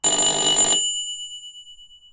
sonnette.wav